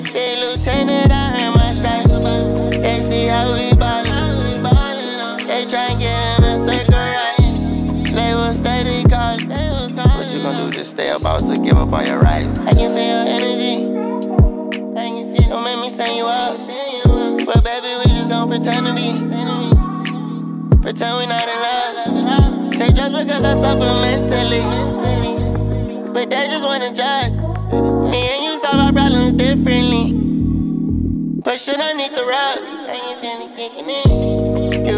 Off-beat гитары и расслабленный ритм
Жанр: Регги